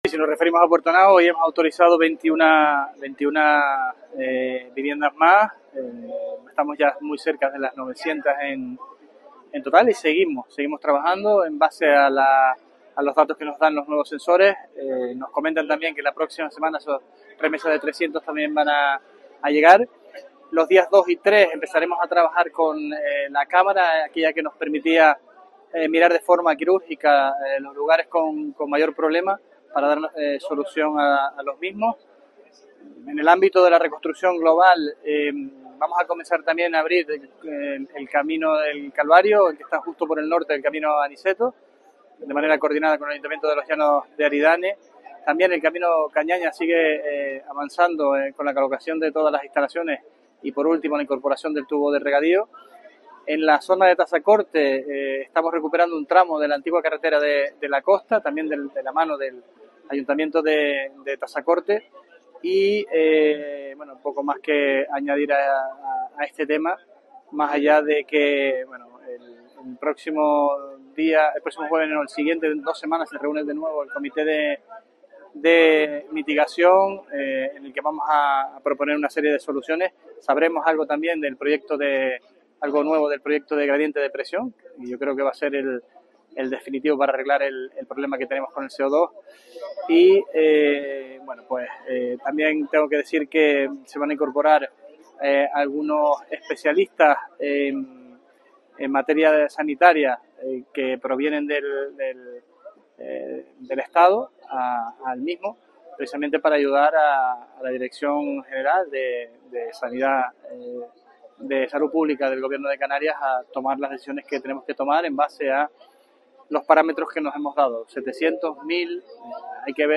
Declaraciones Sergio Rodrígurez Peinpal 26N.mp3